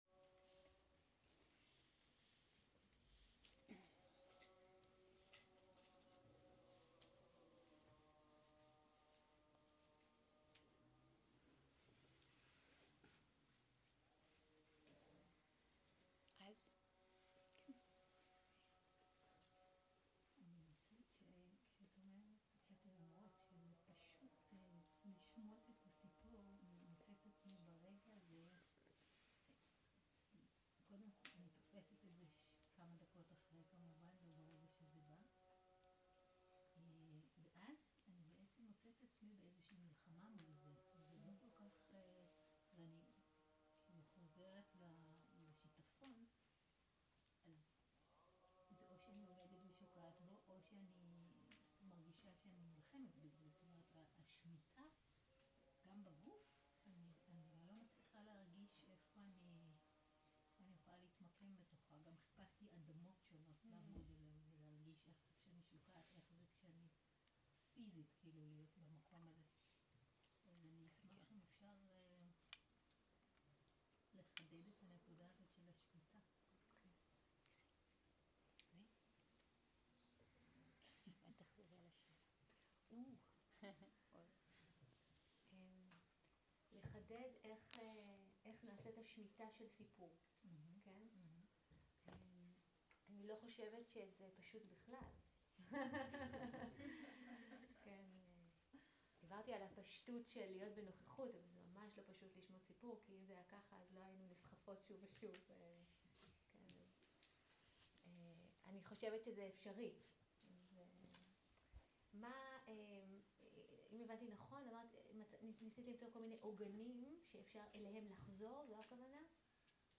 שאלות ותשובות
סוג ההקלטה: שאלות ותשובות
עברית איכות ההקלטה: איכות גבוהה מידע נוסף אודות ההקלטה